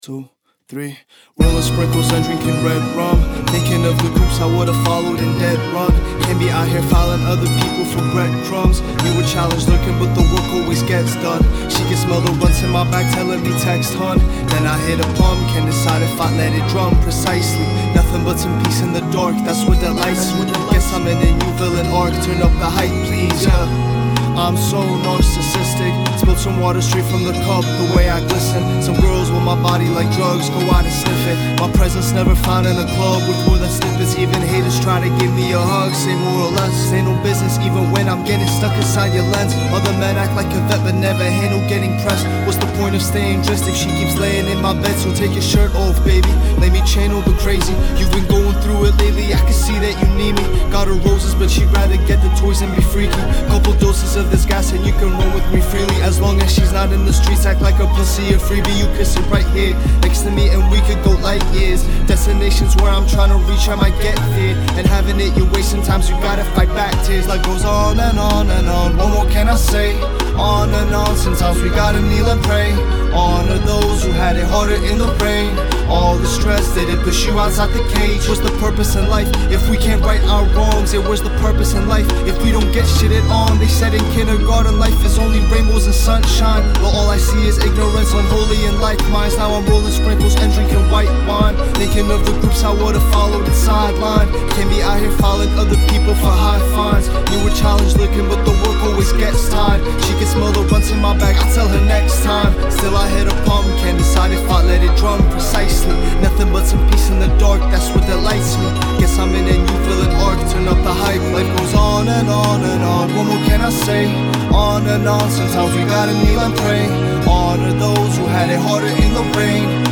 FULL PRODUCTION